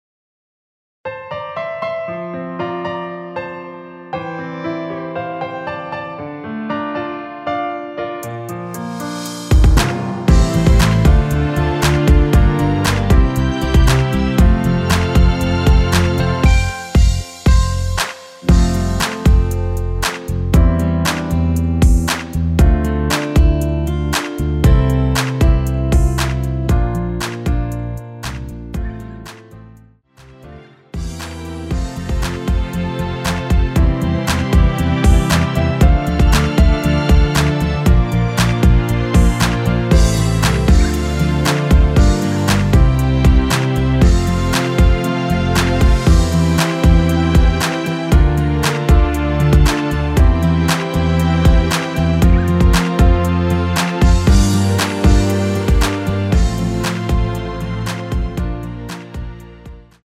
앞부분30초, 뒷부분30초씩 편집해서 올려 드리고 있습니다.
중간에 음이 끈어지고 다시 나오는 이유는